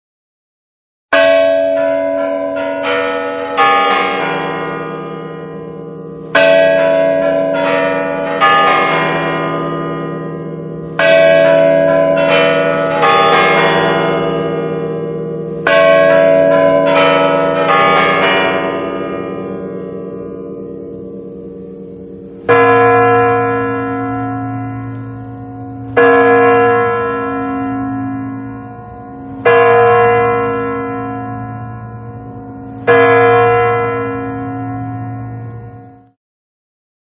» Бой крумлевских курантов Размер: 293 кб